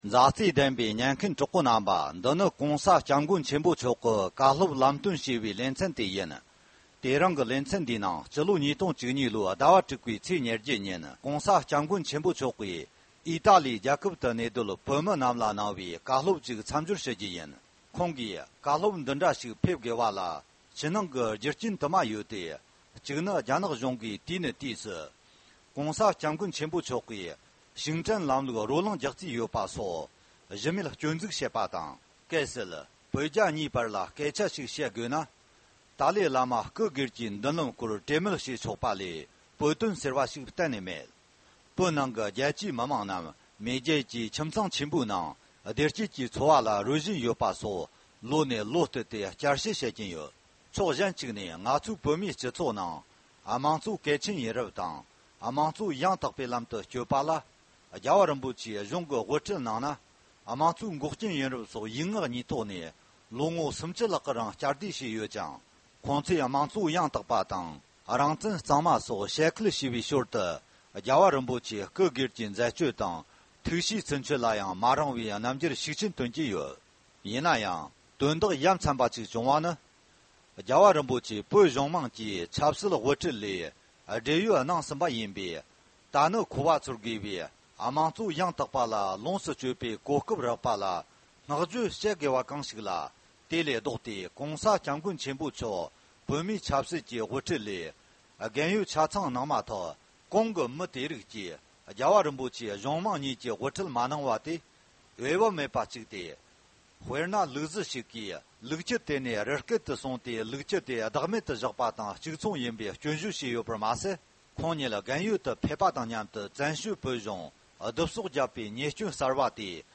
༸གོང་ས་༸སྐྱབས་མགོན་ཆེན་པོ་མཆོག་ནས་སྤྱི་ལོ་༢༠༠༧ལོར་ཨ་མེ་རི་ཀའི་ནུབ་ཕྱོགས་མངའ་སྡེ་ཀེ་ལོ་ཕོར་ནི་ཡའི་གྲོང་ཁྱེར་སེན་ཕརེན་སིསཀོ་ཁུལ་གནས་སྡོད་བོད་མི་རྣམས་ལ་བཀའ་སློབ་སྩལ་བ།